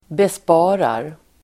Uttal: [besp'a:rar]